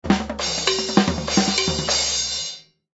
ring_perfect.ogg